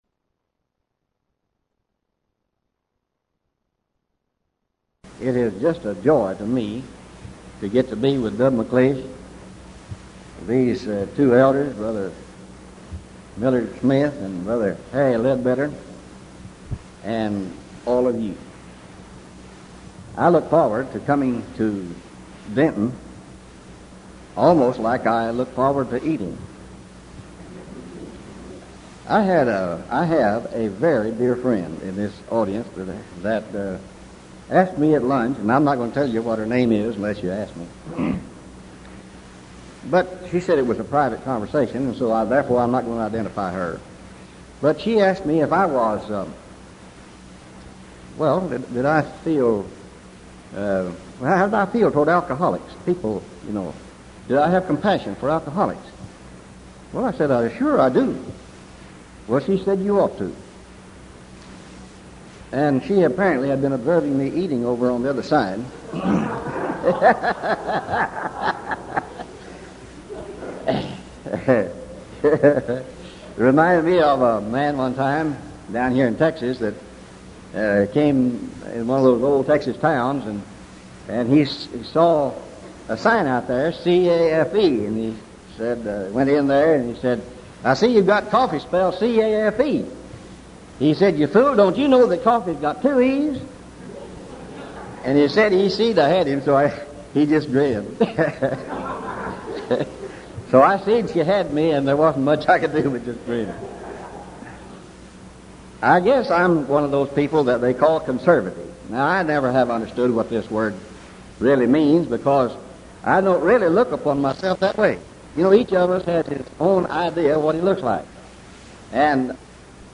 Event: 1986 Denton Lectures Theme/Title: Studies in Galatians
lecture